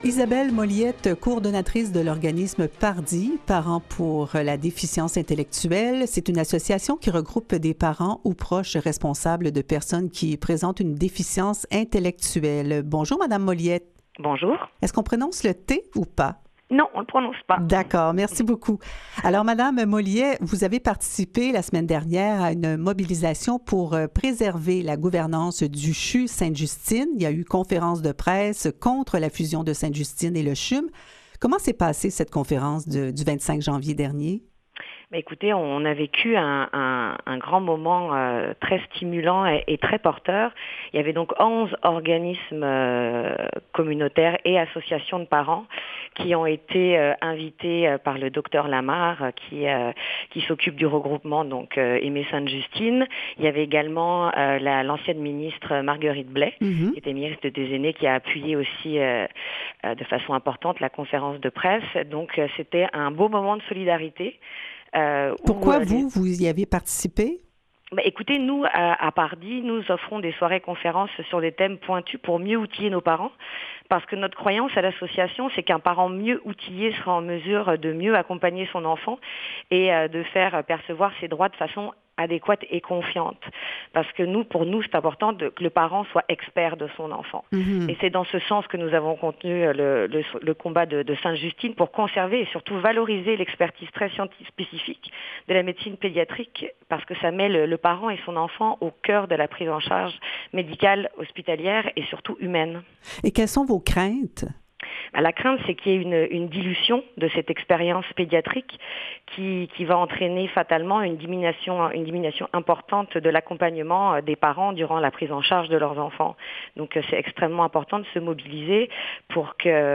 LES ENTREVUES DU JOUR